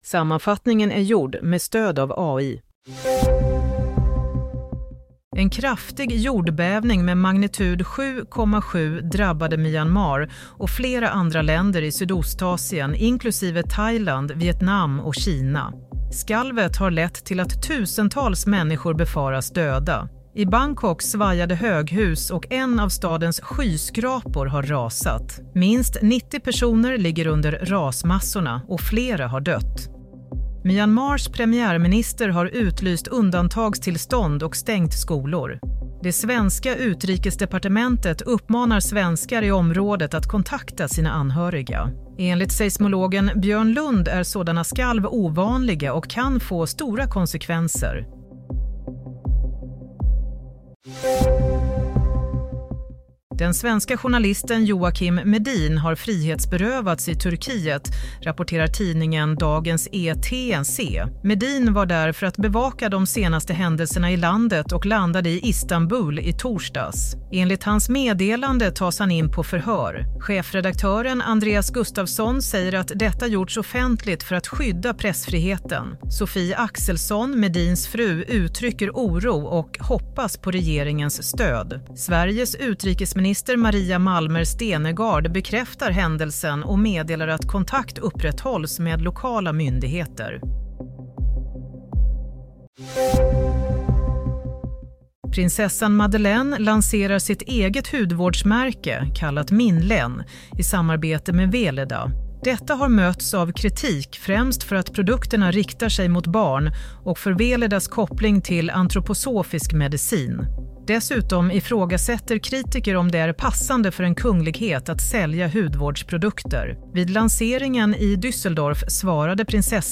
Nyhetssammanfattning – 28 mars 16:00